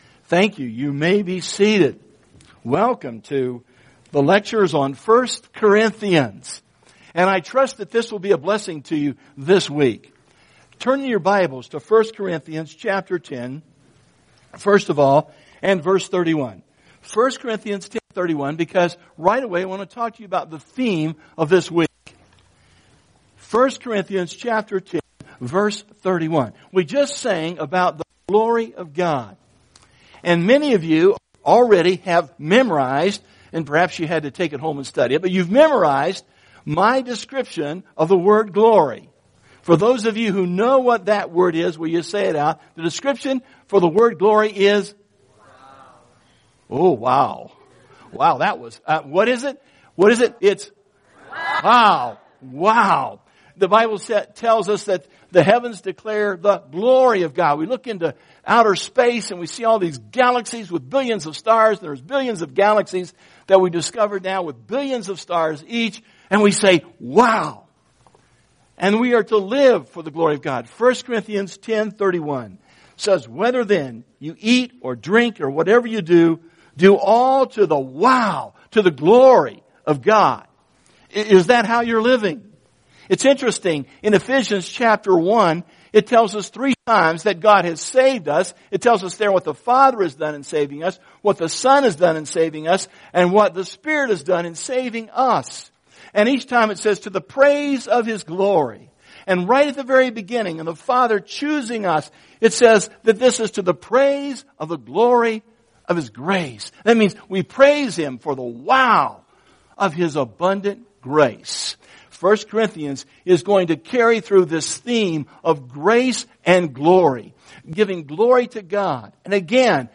Lectures
For this week, all normal classes are suspended, and the entire student body attends the lecture together. The lecture itself is one entire course packed into a week.